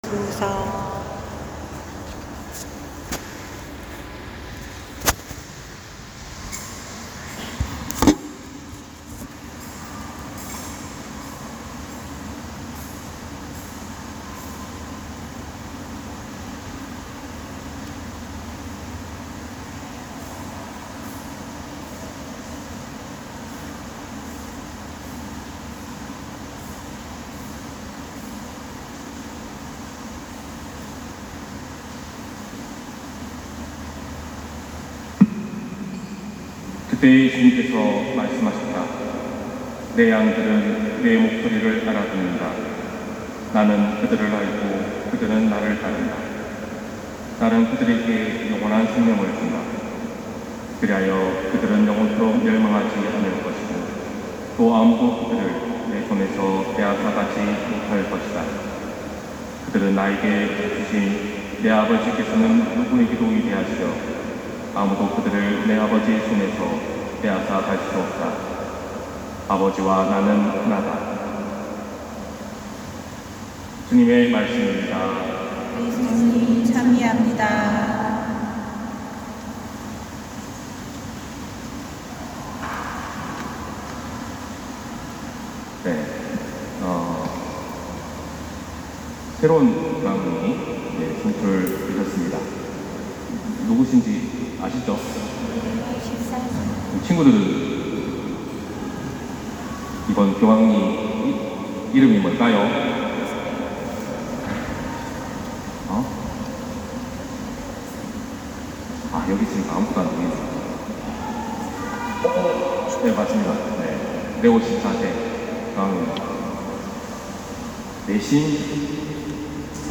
250510 신부님강론말씀